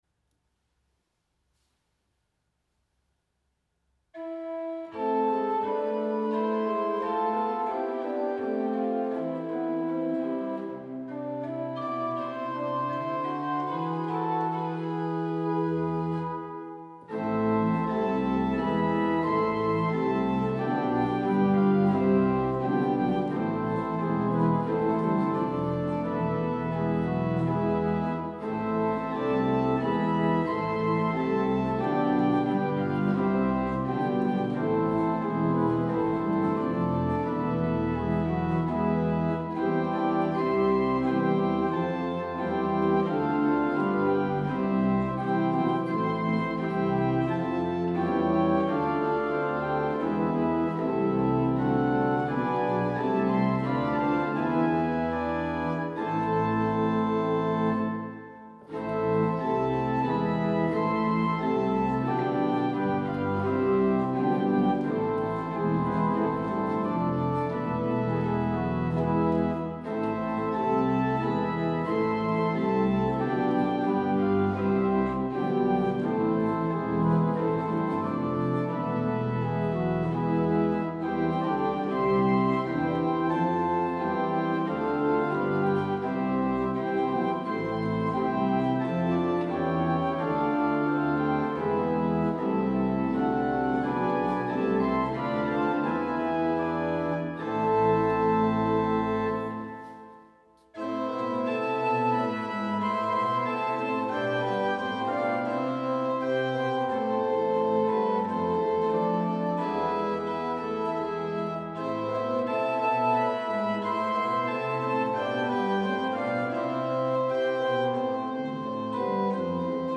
Choräle für Advent und Weihnachtszeit an Orgeln des Kirchenkreises